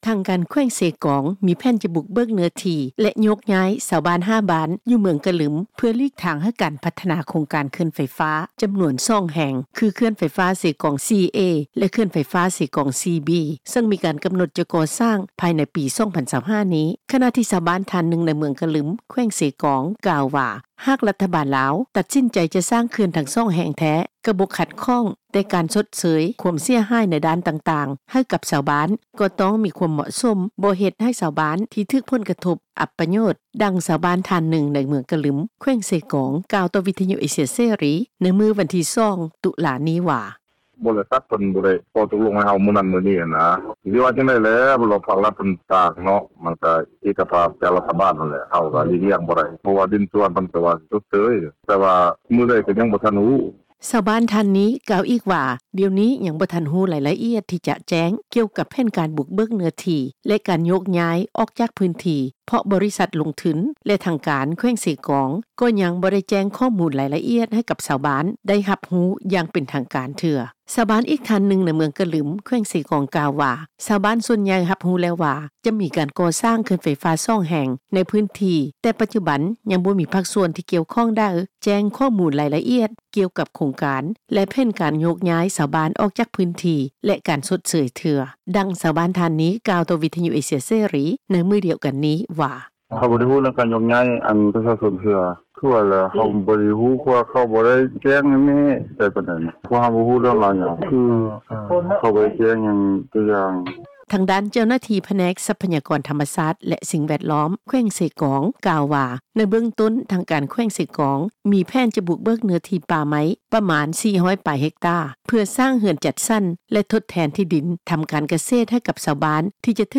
ດັ່ງ ຊາວບ້ານທ່ານນຶ່ງ ໃນເມືອງກະລຶມ ແຂວງເຊກອງ ກ່າວຕໍ່ວິທຍຸ ເອເຊັຽເສຣີ ໃນມື້ວັນທີ 2 ຕຸລາ ນີ້ວ່າ:
ດັ່ງ ເຈົ້າໜ້າທີ່ນາງນີ້ ກ່າວຕໍ່ວິທຍຸ ເອເຊັຽເສຣີ ໃນມື້ດຽວກັນນີ້ວ່າ: